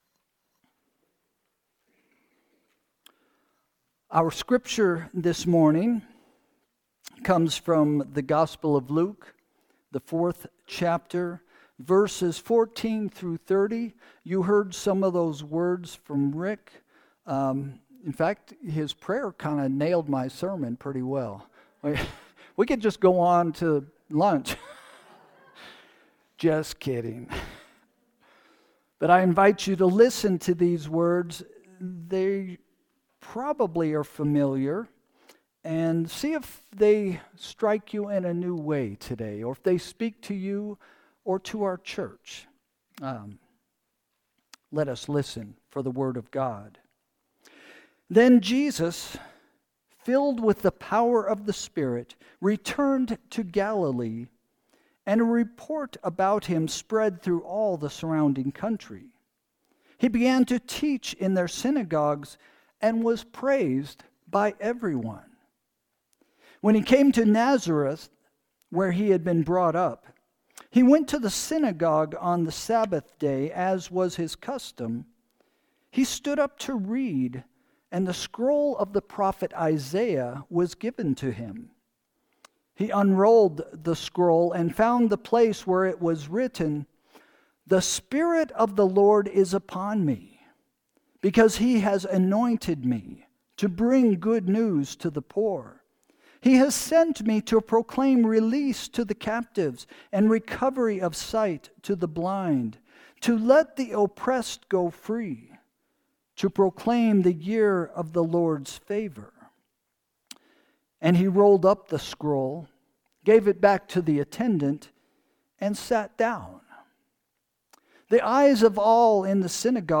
Sermon – March 16, 2025 – “What I Want to Hear”